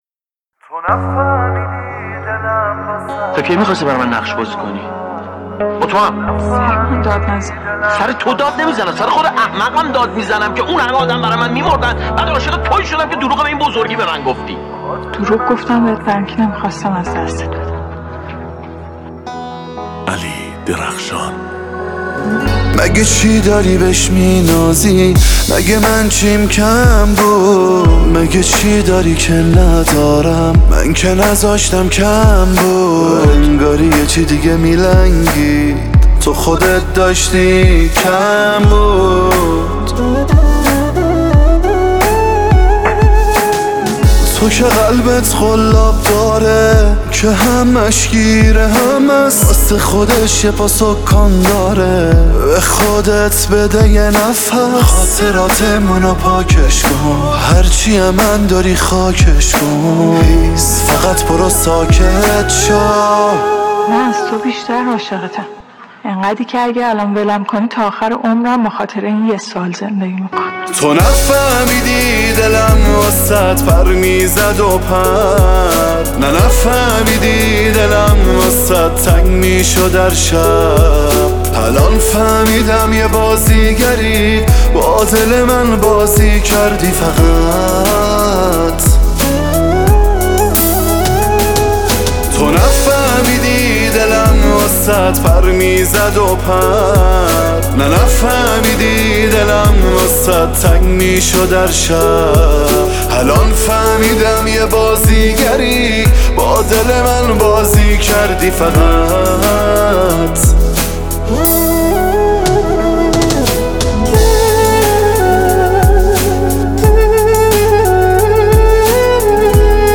اهنگ غمگین عاشقانه